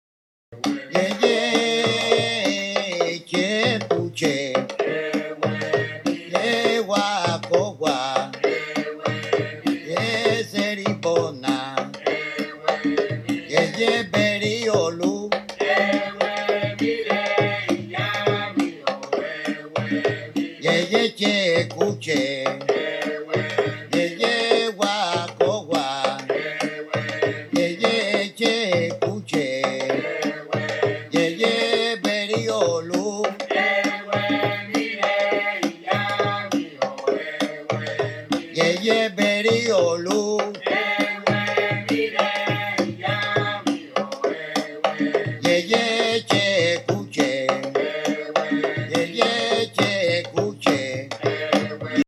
chorus and percussion